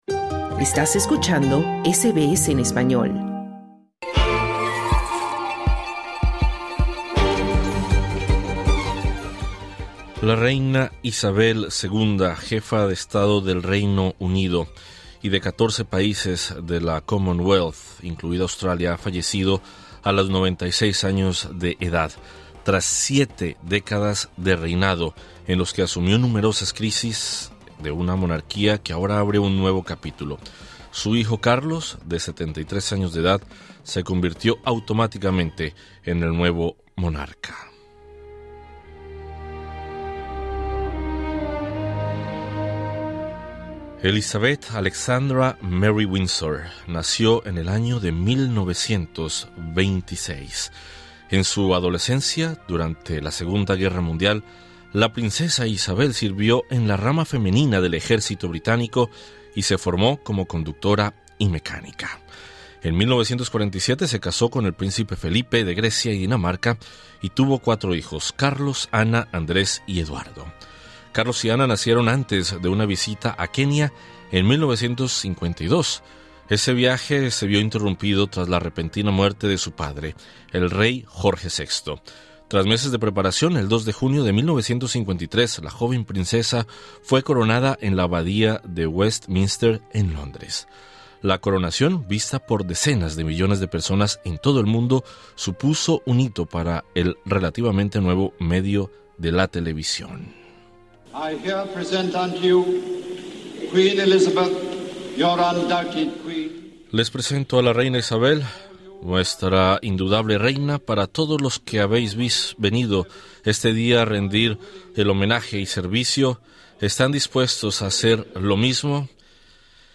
Los líderes políticos australianos y miembros de la comunidad de habla hispana en Australia rinden homenaje a la reina Isabel II, por el trabajo que desempeñó en los países de la Commonwealth, particularmente en Australia durante más de 70 años. Escucha el informe.